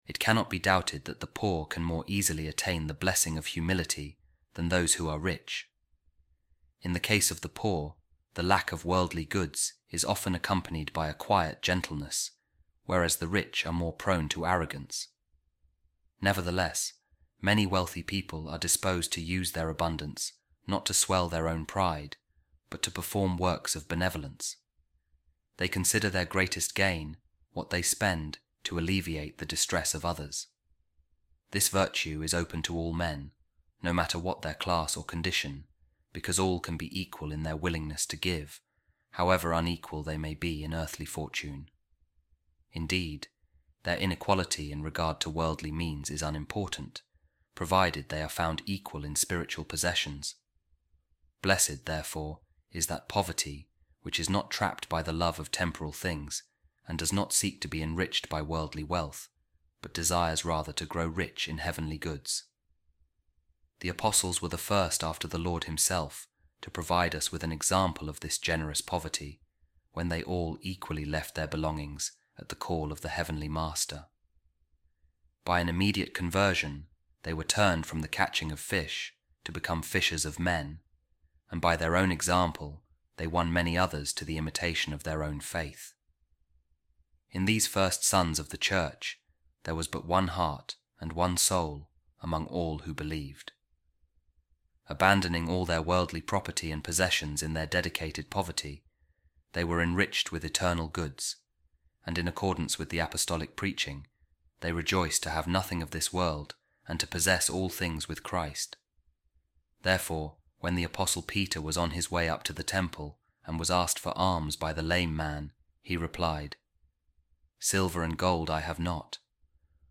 A Reading From The Sermon Of Pope Saint Leo The Great On The Beatitudes | Blessed Are The Poor In Spirit